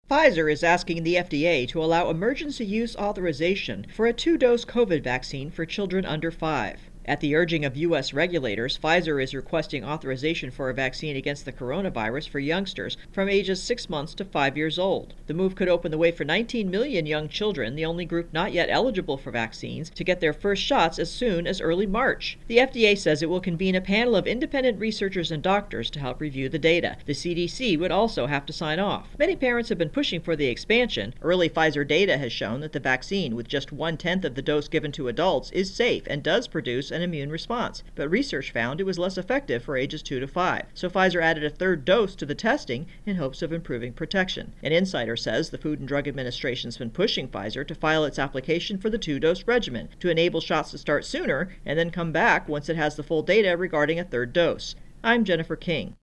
Virus Outbreak Kid Vaccines intro and voicer-updated